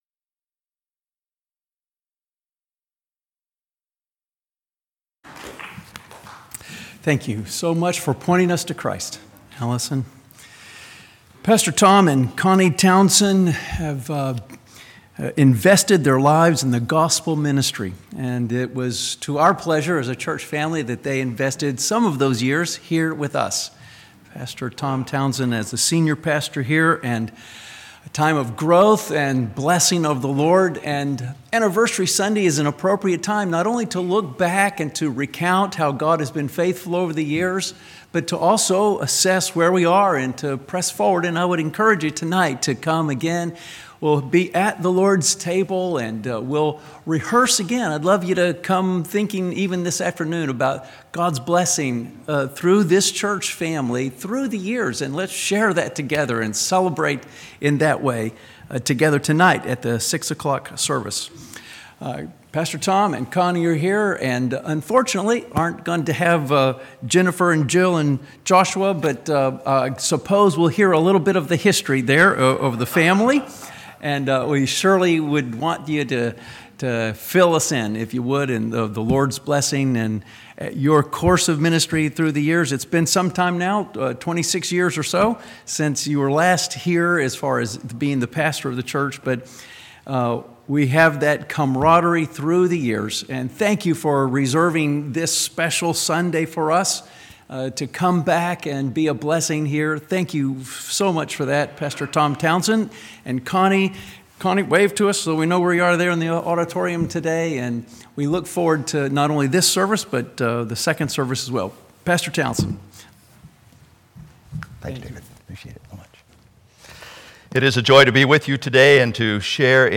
87th Anniversary Sunday